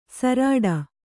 ♪ sarāḍa